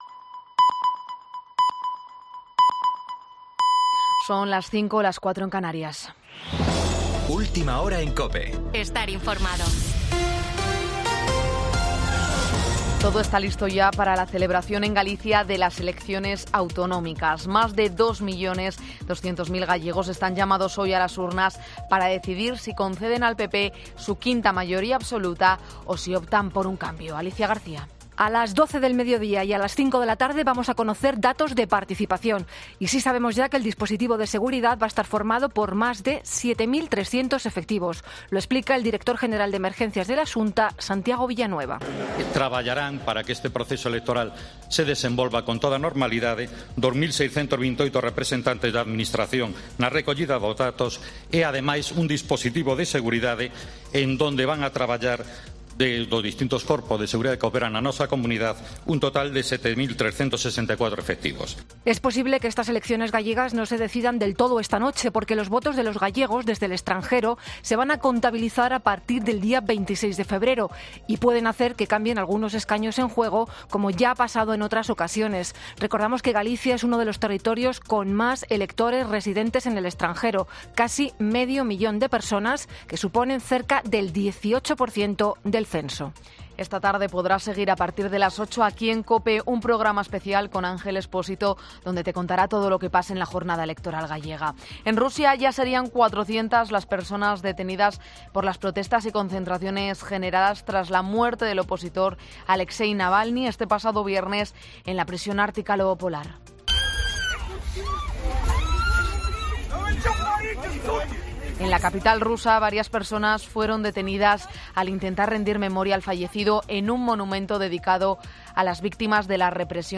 Boletín 05.00 horas del 18 de febrero de 2024